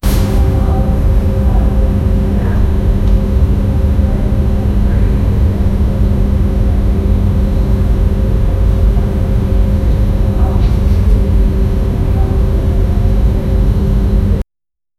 Only noise of silence place with far crowded
only-noise-of-silence-pla-vrylypnd.wav